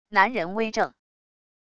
男人微怔wav音频